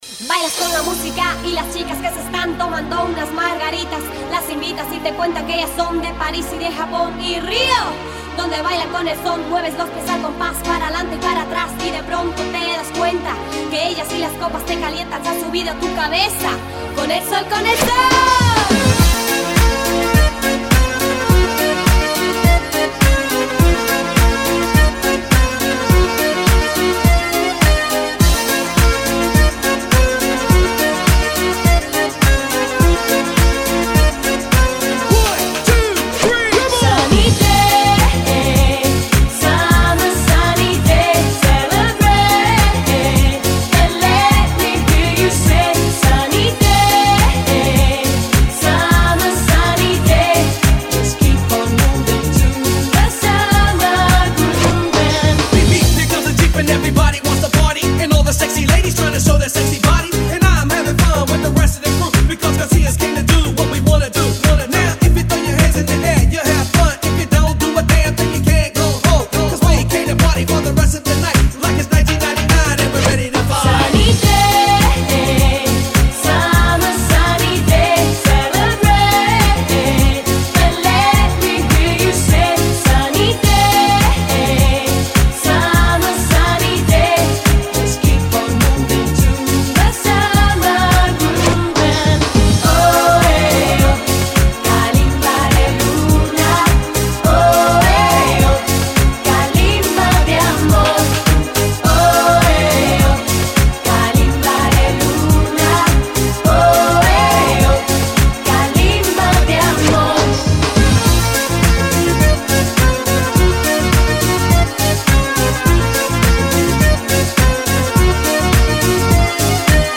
Пару позитивных песен из 90-х